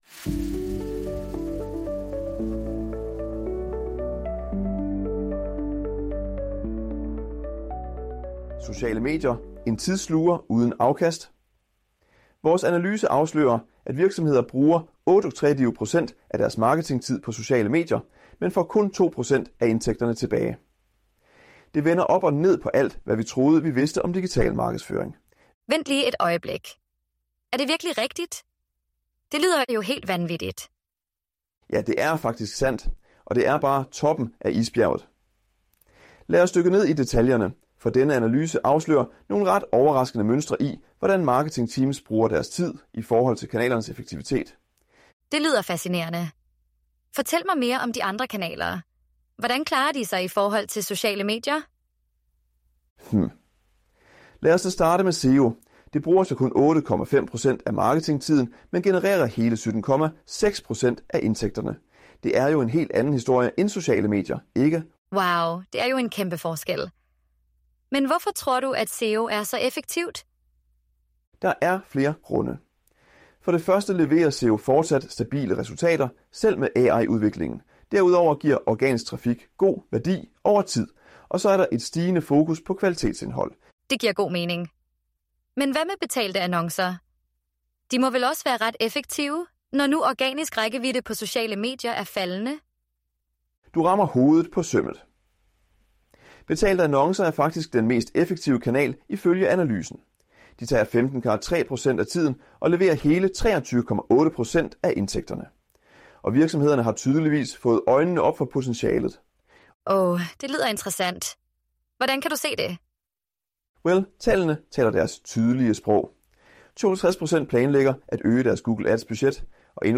I denne samtale tales der om de udfordringer, virksomheder står overfor med sociale medier, og hvordan de kan optimere deres marketingstrategi. Analyser viser, at sociale medier bruger 38 % af marketingtiden, men kun genererer 2 % af indtægterne.